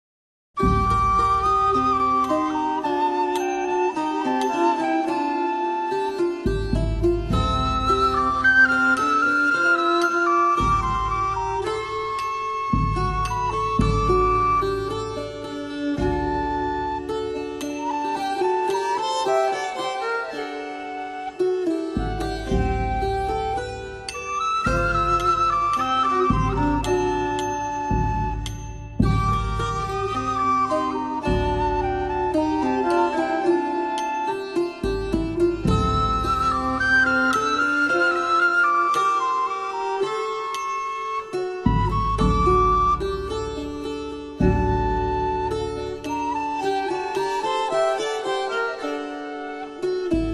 Diffusion distribution ebook et livre audio - Catalogue livres numériques
Il est accompagé par le « Concert dans l?oeuf » qui joue des cantigas, des musiques de trouvères, des extraits du Manuscrit de Montpellier, des airs de Shami Lyad Haimour ou d?Alphonse X le Sage, sur des instruments d?époque. 14 , 20 € Prix format CD : 19,99 € Ce livre est accessible aux handicaps Voir les informations d'accessibilité